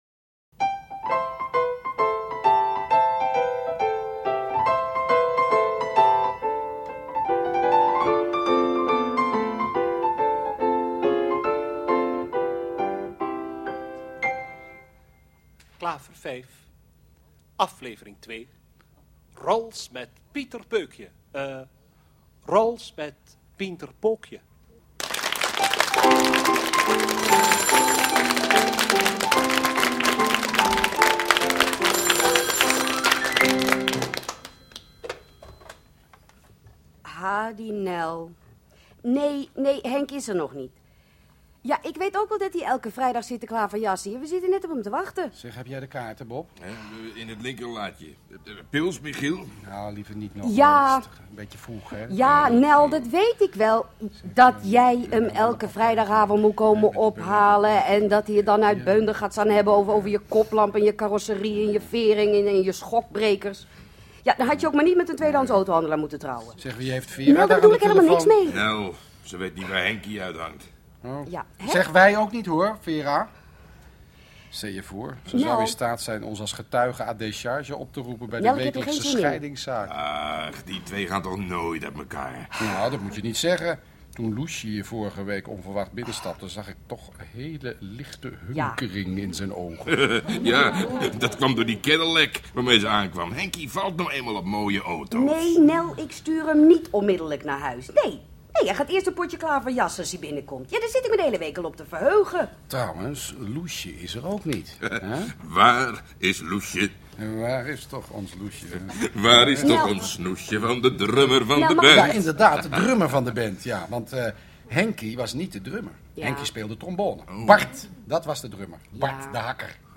Drie heren en twee dames weten, onder het klaverjassen door, elkaar en de luisteraar te vermaken door middel van geplande en niet geplande anekdotes.
Ted de Braak als stamgast Humor (KRO) 6 delen Verhaal: Een aantal vrienden heeft een klaverjasclub.
Deze hoorspelserie bestaat uit 6 afleveringen van elk ongeveer 25 minuten.